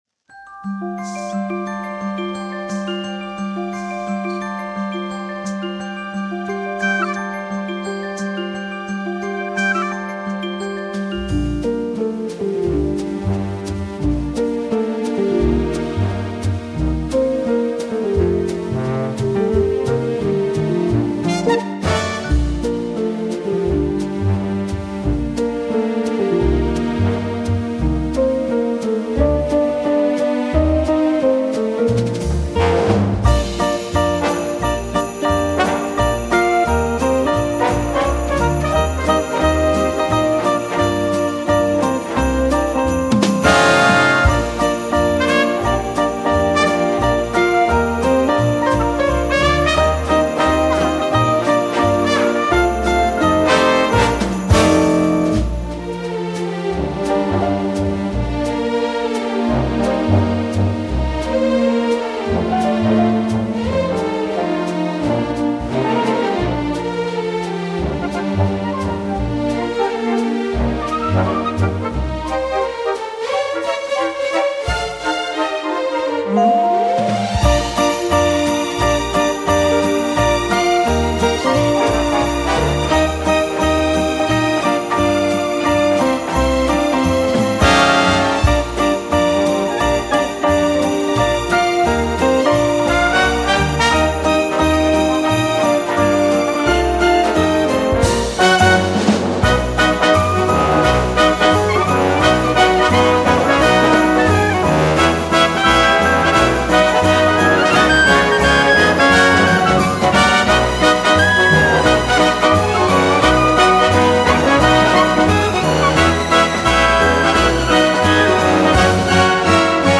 jingle bells jazz version 🎵